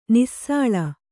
♪ nissāḷa